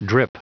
Prononciation du mot drip en anglais (fichier audio)
Prononciation du mot : drip